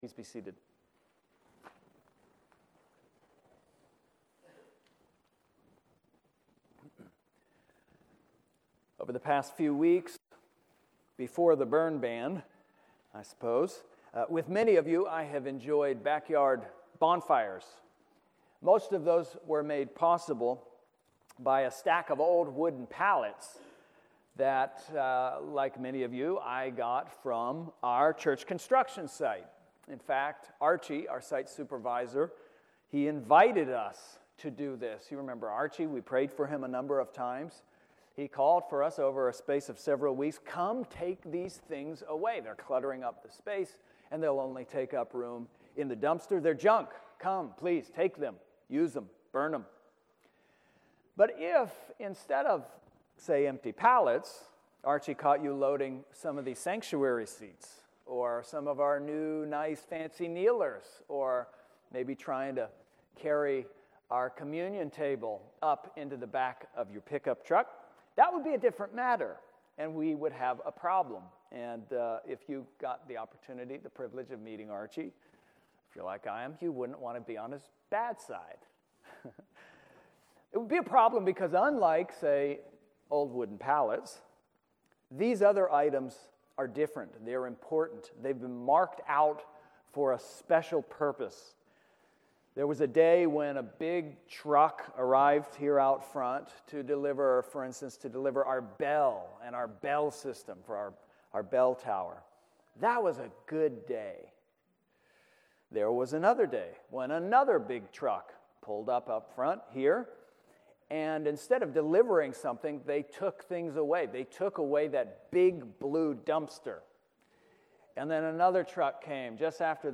Sermons | Trinity Presbyterian Church